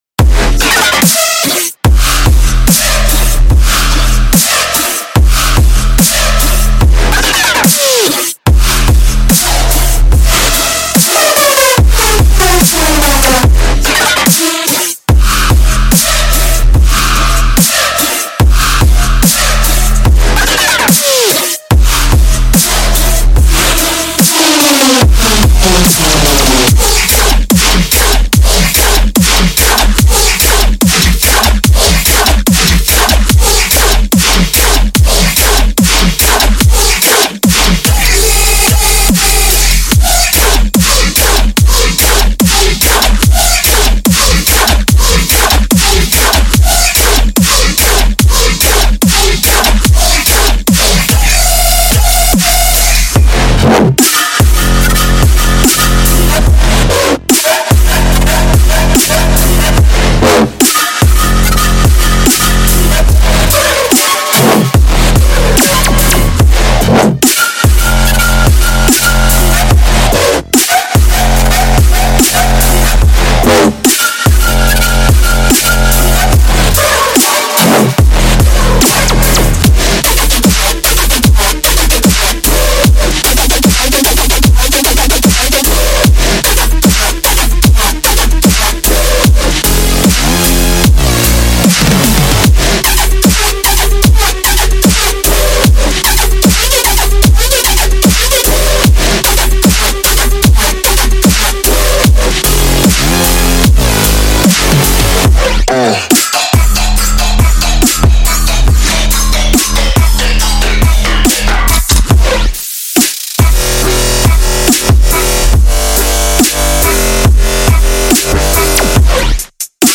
请注意：样本包包含音频演示中介绍的所有声音。
• 15个完全干剥的dubstep滴剂
• 140-150 BPM
• 包括鼓，贝斯，合成器，效果